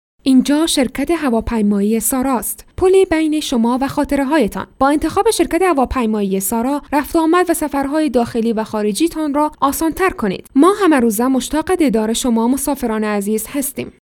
Female
Adult
commercial